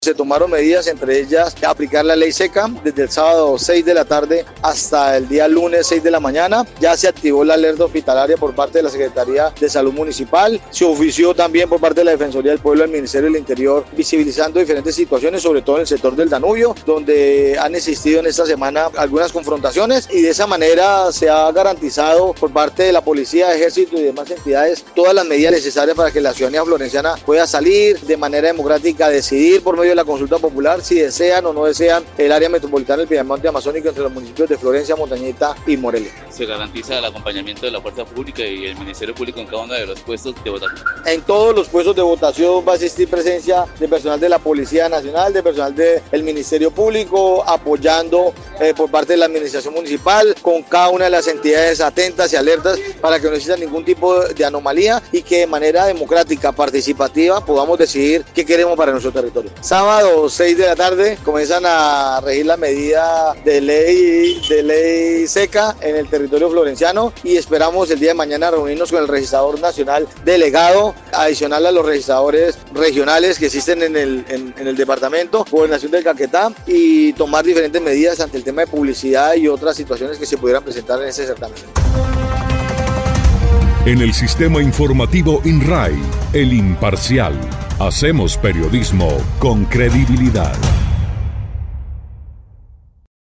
Carlos Mora, secretario de gobierno municipal explicó que la medida fue concertada entre las autoridades en el más reciente comité de seguimiento electoral y de seguridad y convivencia ciudadana, donde además se acordó garantizar la seguridad en todos los rincones de la capital.